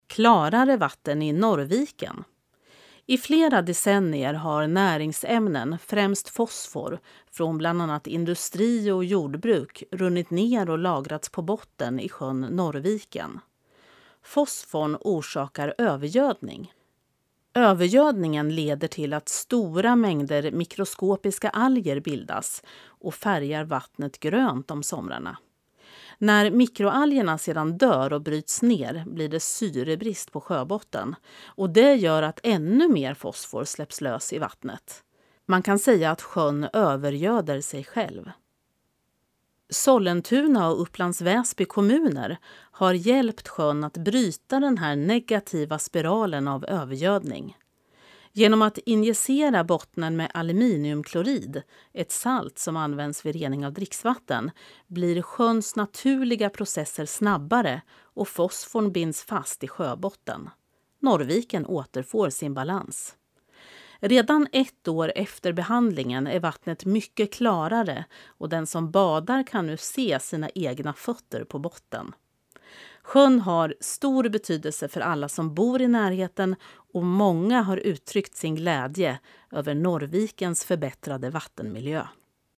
Klicka här för att lyssna till texten, inläst av professionell uppläsare